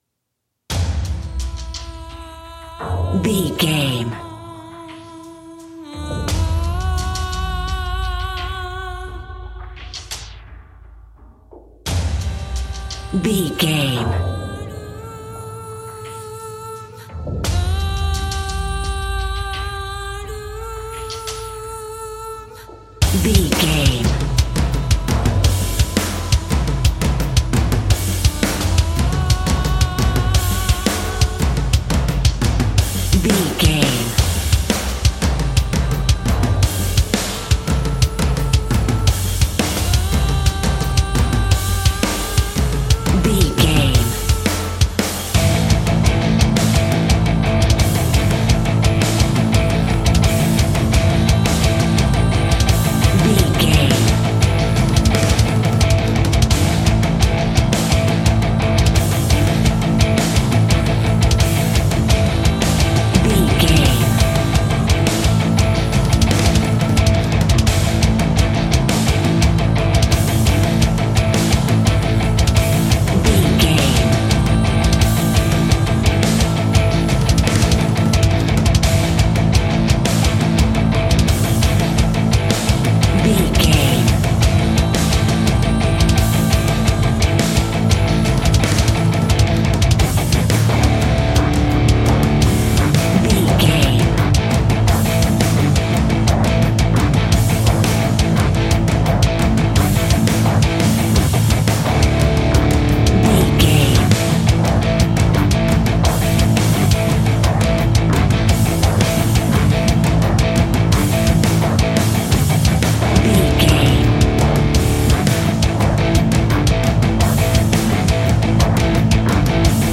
Epic / Action
Fast paced
Aeolian/Minor
guitars
heavy metal
dirty rock
Heavy Metal Guitars
Metal Drums
Heavy Bass Guitars